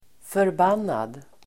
Uttal: [förb'an:ad]